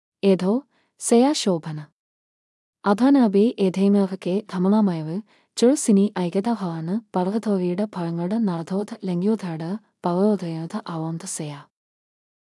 SobhanaFemale Malayalam AI voice
Sobhana is a female AI voice for Malayalam (India).
Voice sample
Listen to Sobhana's female Malayalam voice.
Female
Sobhana delivers clear pronunciation with authentic India Malayalam intonation, making your content sound professionally produced.